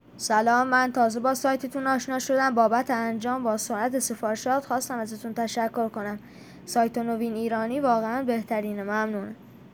نظرات مثبت مشتریان عزیزمون با صدا خودشون رضایت از سایت نوین ایرانی